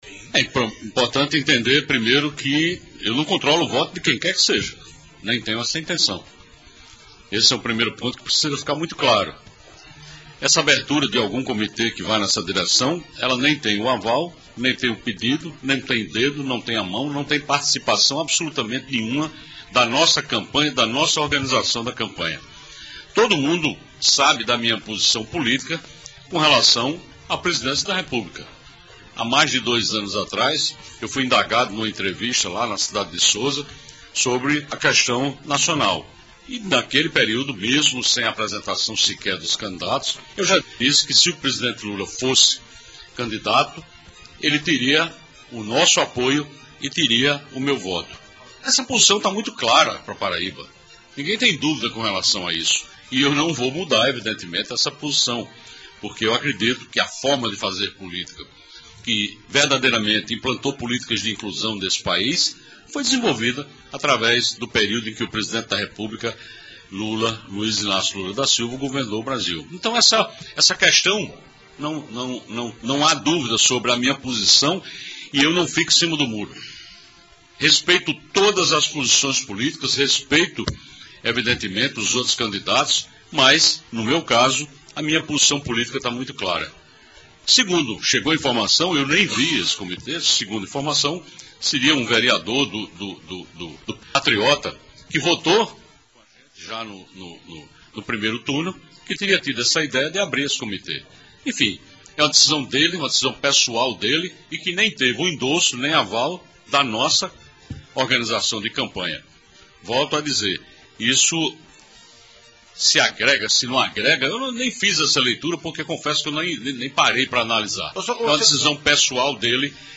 Durante sabatina no programa Arapuan Verdade, do Sistema Arapuan de Comunicação, o chefe do Executivo paraibano relembrou que é apoiador de Lula (PT) e destacou que não controla votos ao ressaltar que a criação não teve aval de sua campanha.